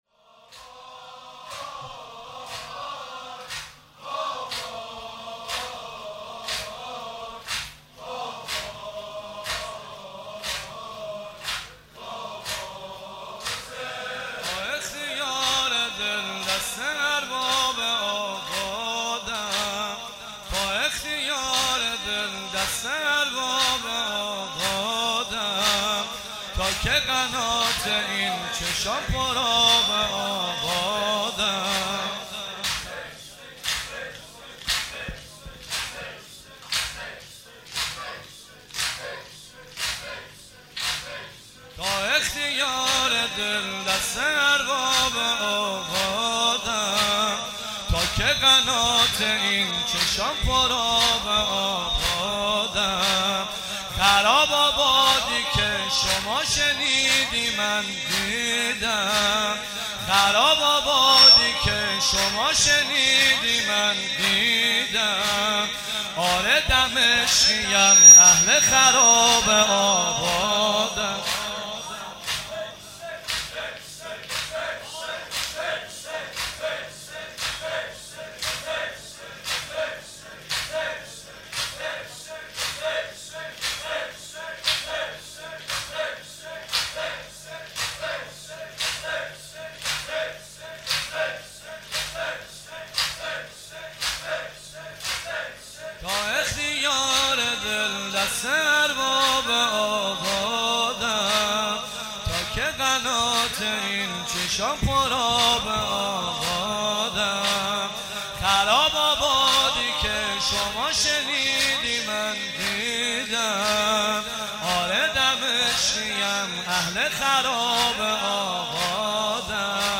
مناسبت : شب سوم محرم
قالب : زمینه